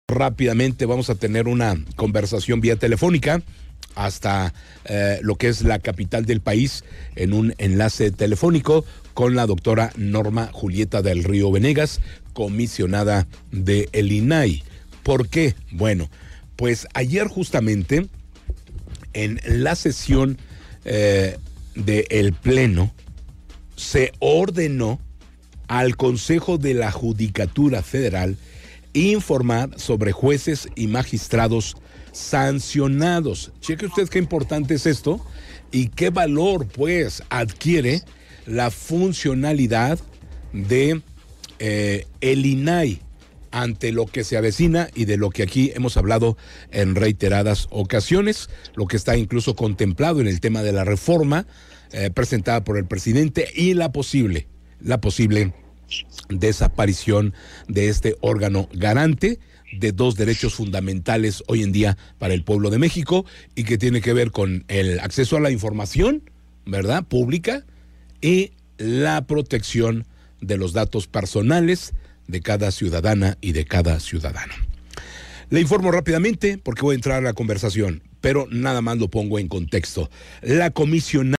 Entrevista
en Estéreo Zer Zacatecas